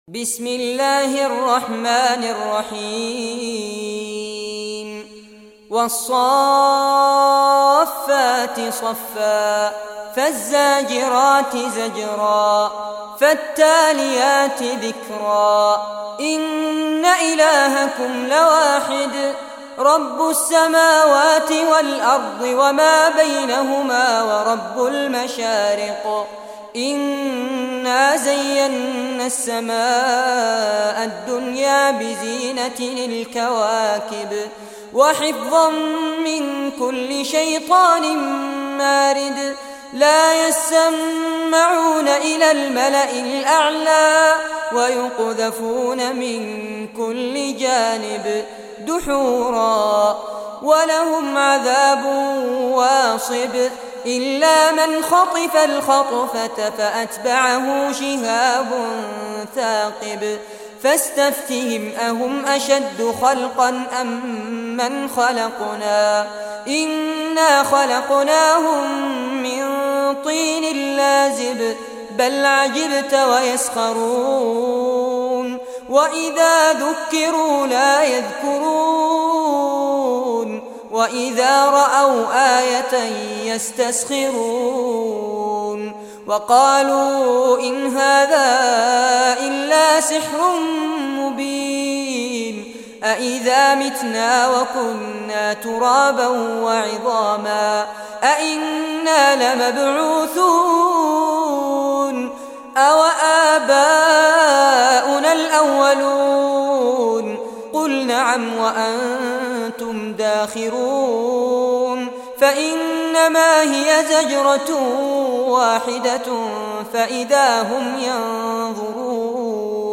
Surah As-Saffat Recitation by Fares Abbad
Surah As-Saffat, listen or play online mp3 tilawat / recitation in Arabic in the beautiful voice of Sheikh Fares Abbad.